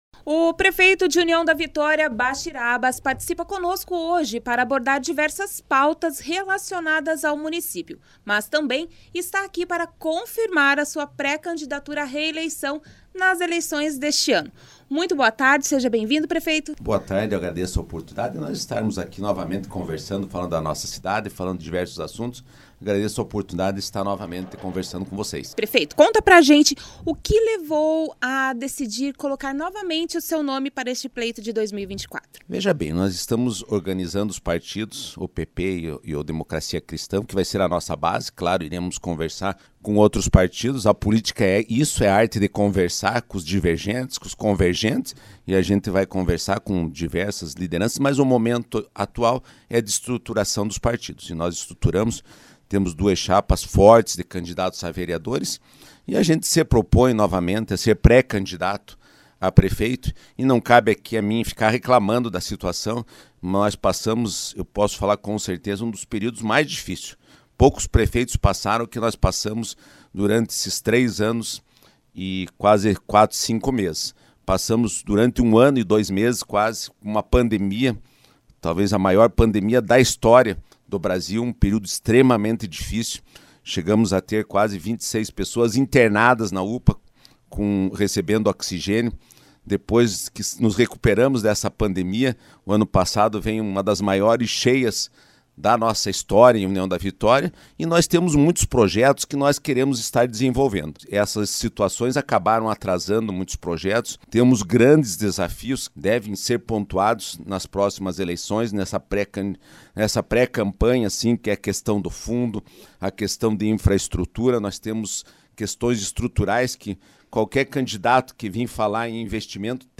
Entrevista com prefeito Bachir Abbas.
O prefeito de União da Vitória Bachir Abbas participou nesta sexta-feira,03, de uma entrevista no jornal Uniguaçu Noticias, onde abordou diversas pautas do município e também confirmou a sua pré-candidatura a reeleição nas eleições de 2024.